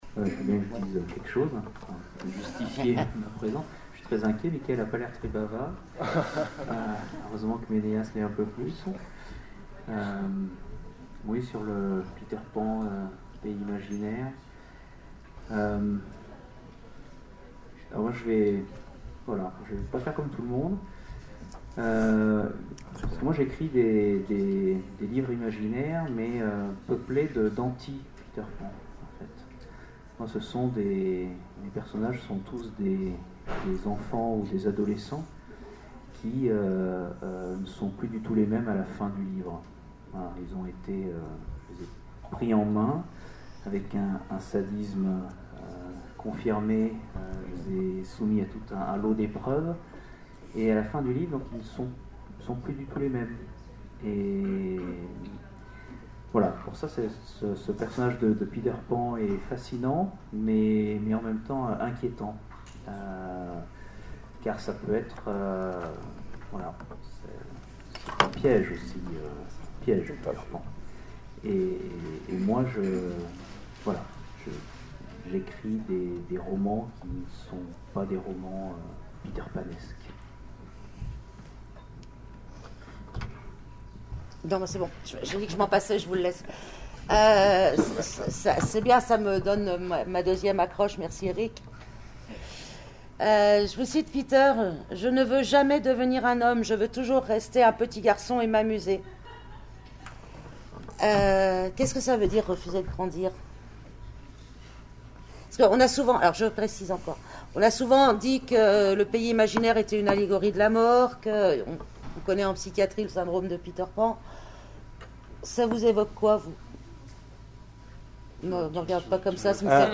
Les oniriques 2017 : conférence Peter Pan, l’Ante-Métamorphe